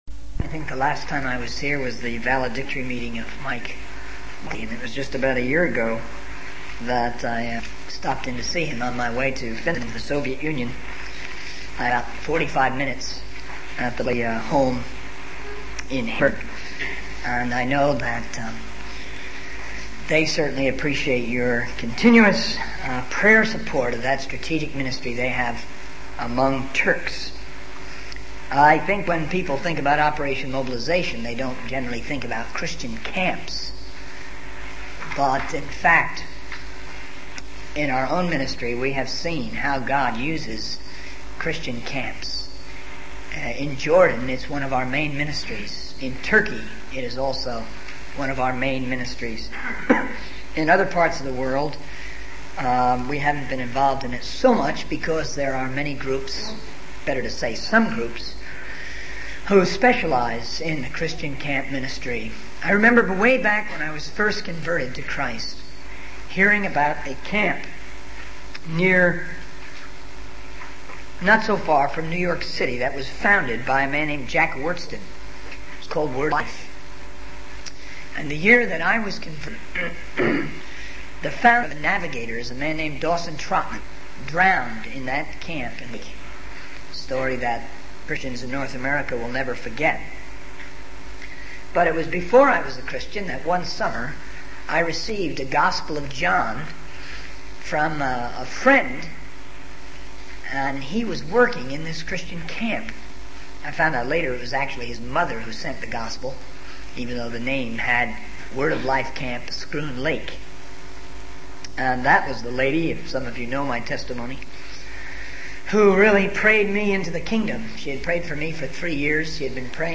In this sermon, the speaker begins by sharing about his recent trip to Wales and expresses his desire to keep the sermon short so that the audience can go home and rest. He then mentions the importance of being witnesses in different geographical zones, specifically Jerusalem, Judea, Samaria, and the uttermost part of the earth, as stated in Acts 1:8. The speaker emphasizes the need to have a vision beyond one's own area and to spread the gospel to the ends of the earth.